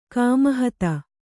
♪ kāmahata